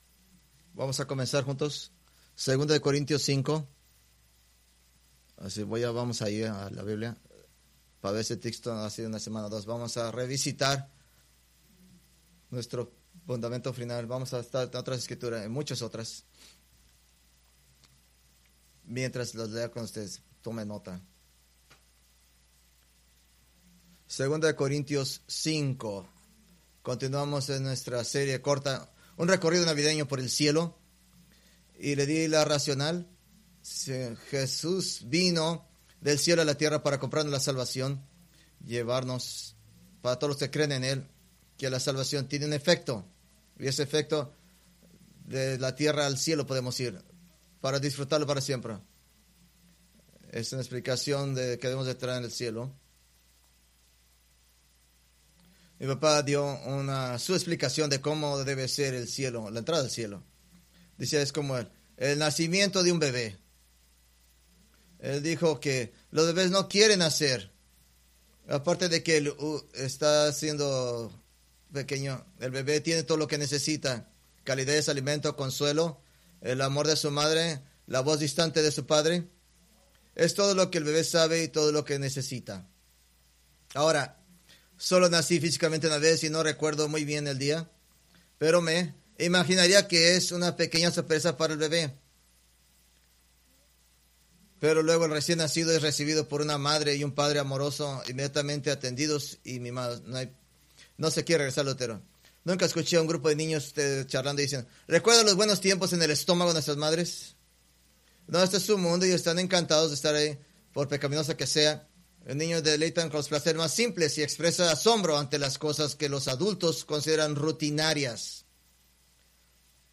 Preached December 22, 2024 from Escrituras seleccionadas